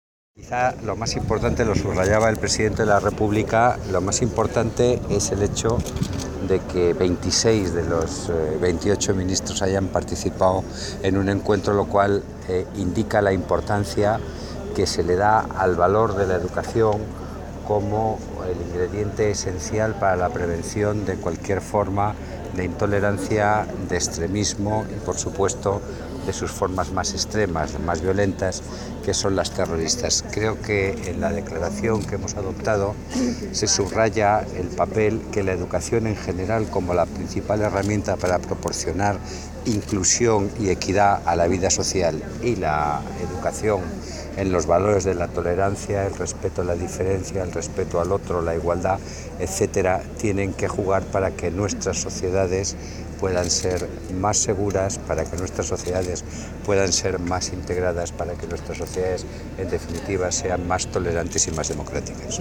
Declaraciones del ministro de Educación, Cultura y Deporte, José Ignacio Wert.